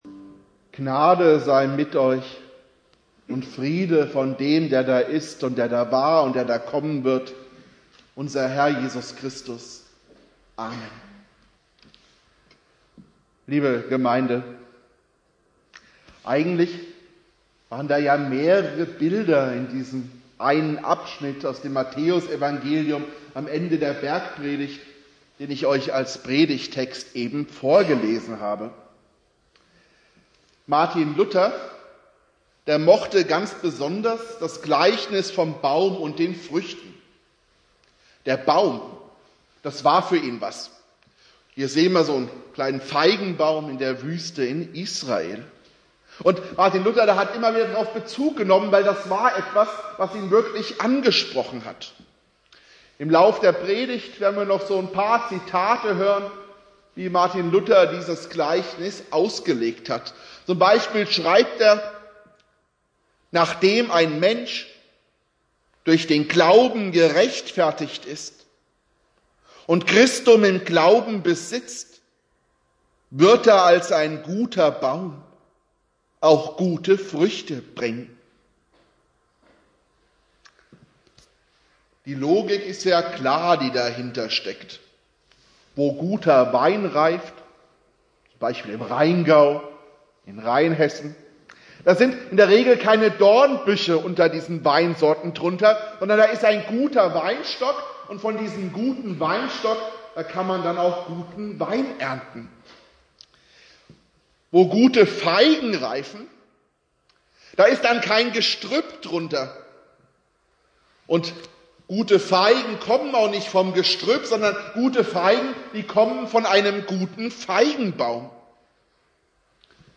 Predigt
Buß- und Bettag Prediger